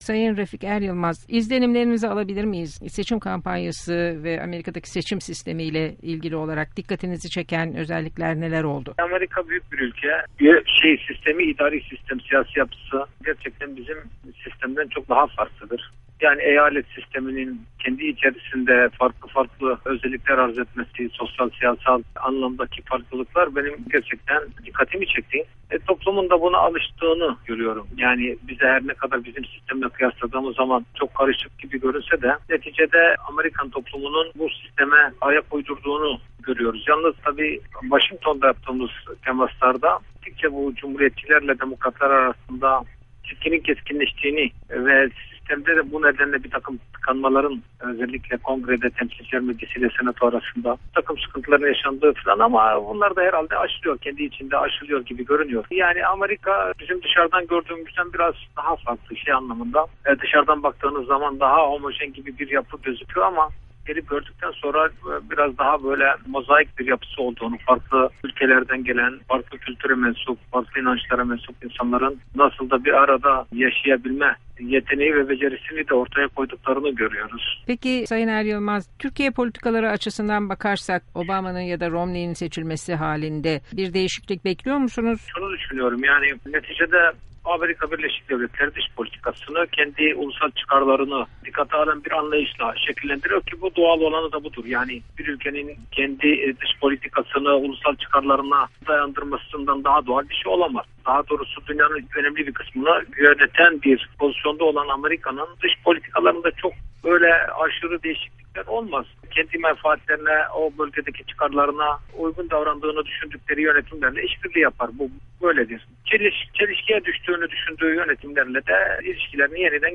Refik Eryılmaz ile Söyleşi